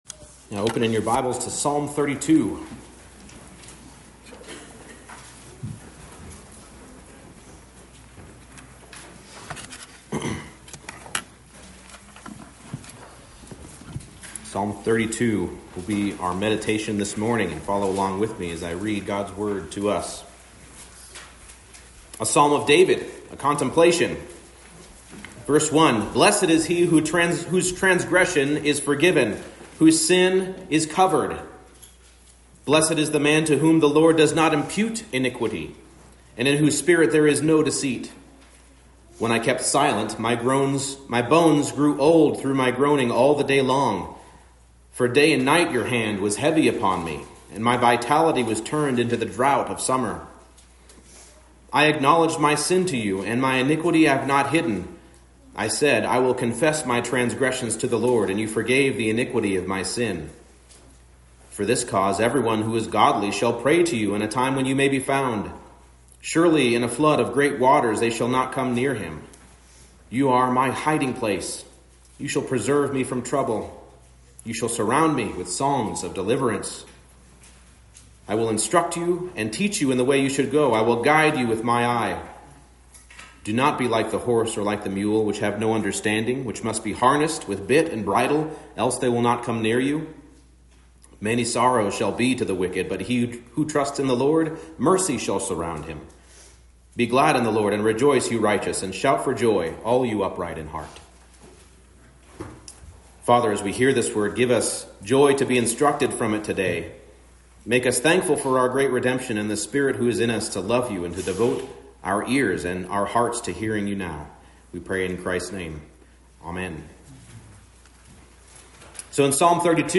Service Type: Morning Service